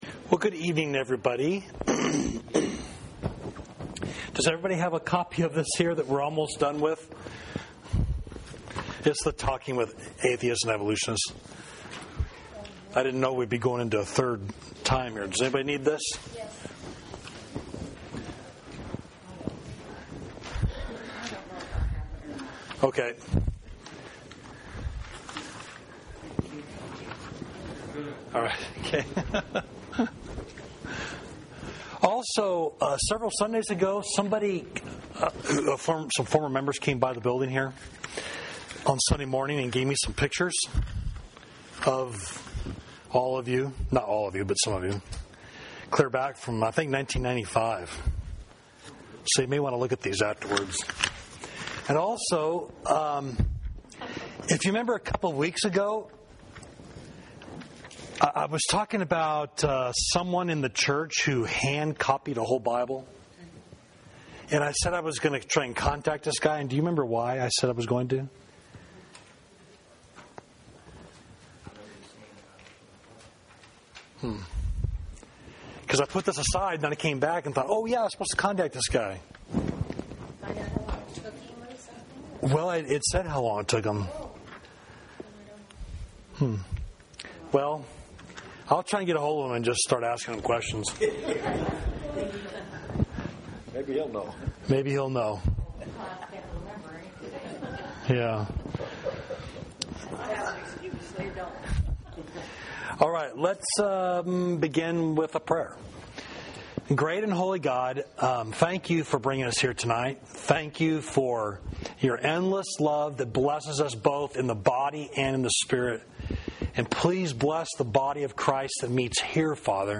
Class: Talking with Atheists and Evolutionists
Adult Wednesday Class